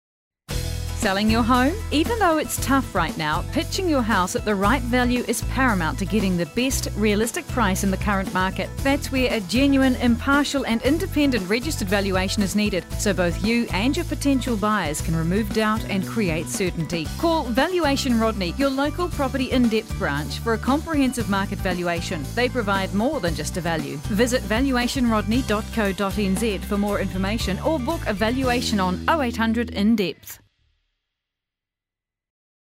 Radio Advertising